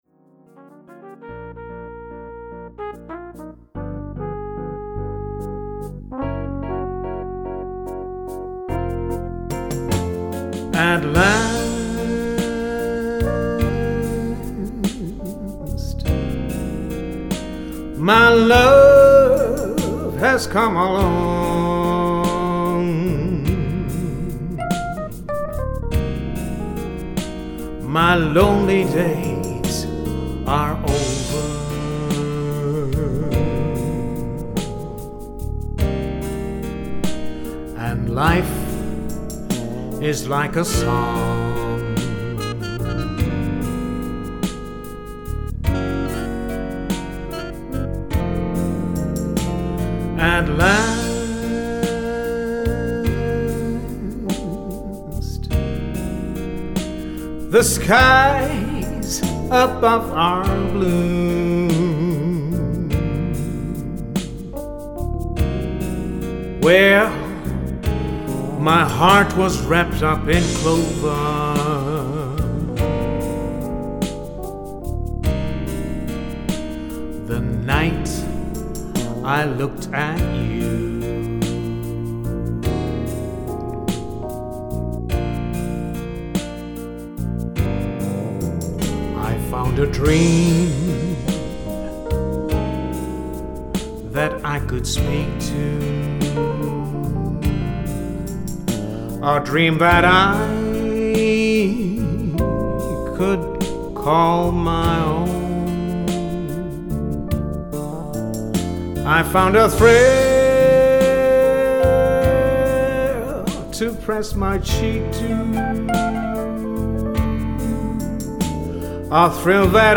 R & B / Motown / Soul